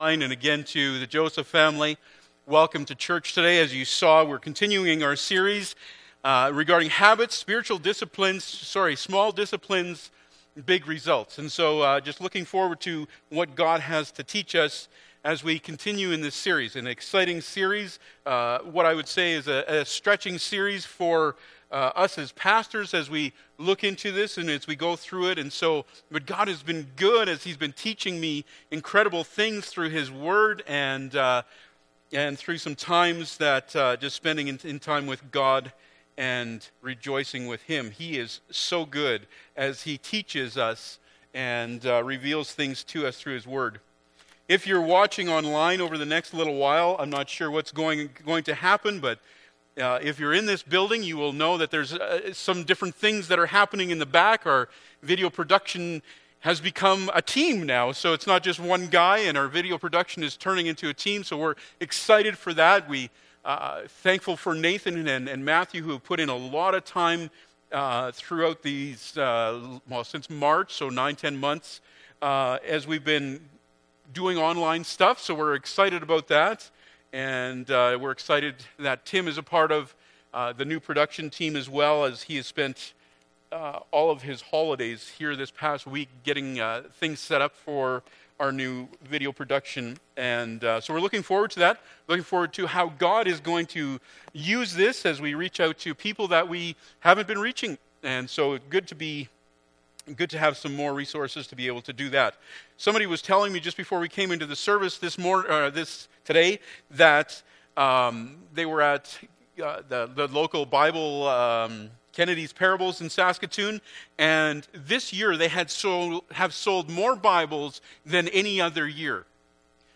Matthew 6:16-18 Service Type: Sunday Morning Bible Text